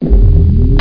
sound / weapons / physgun_loop4.wav
physgun_loop4.mp3